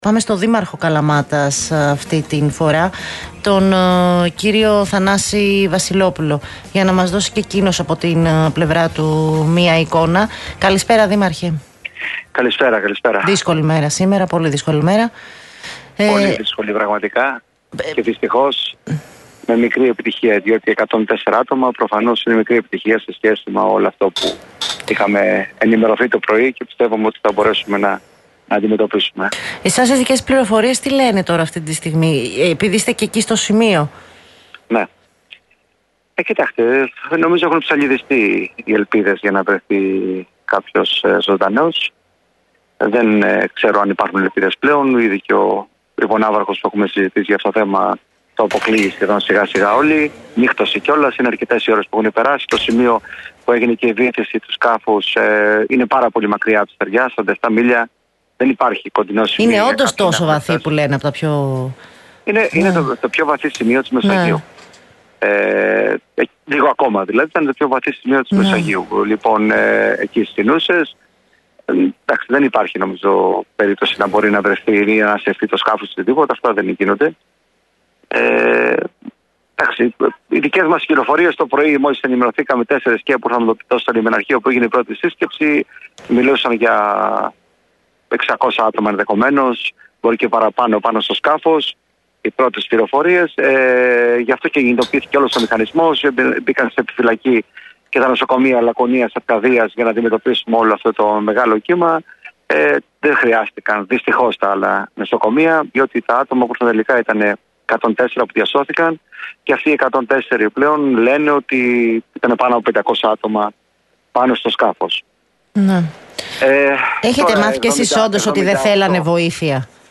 Ο δήμαρχος Καλαμάτας, Θανάσης Βασιλόπουλος ανέφερε ότι οι διασωθέντες αναφέρουν πως πάνω στο σκάφος ήταν περισσότερα από 500 άτομα.